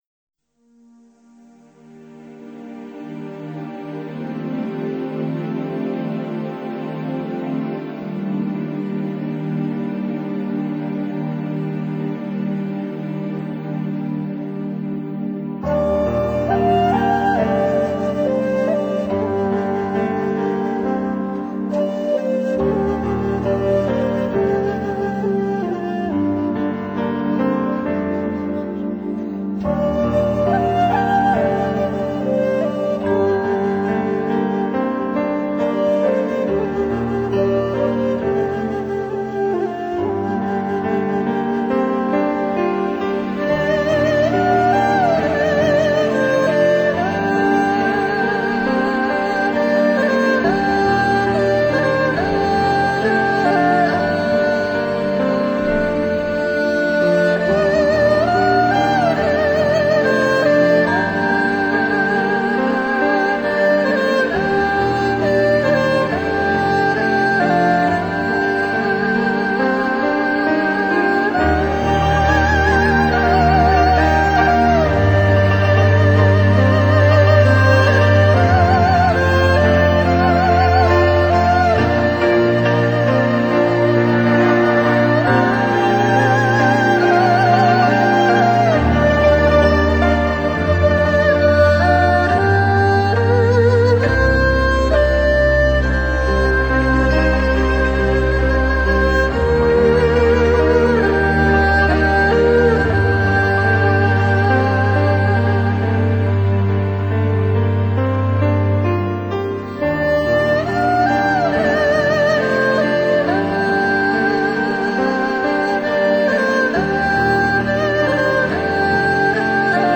healing music